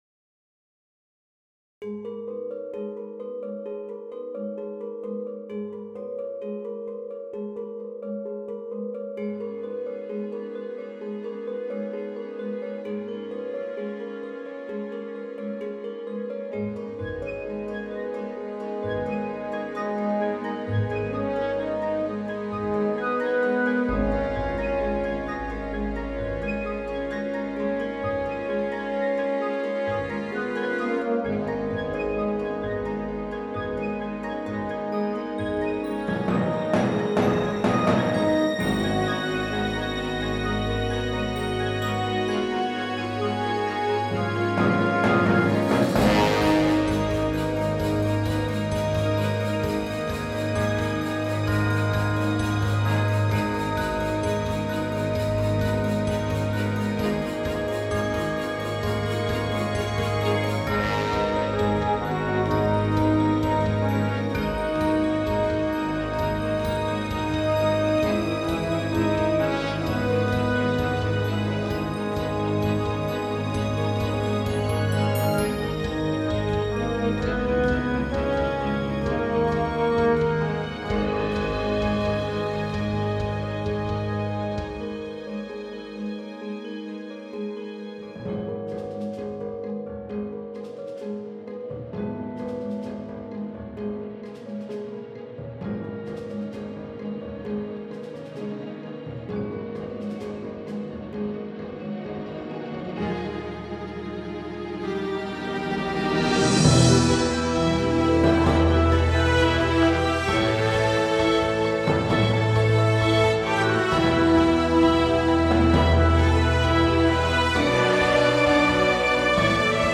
Though it’s not the last one, this track actually scores the finale of the film. It’s dramatic and functions as a pulse in this long scene which covers many perspectives and characters.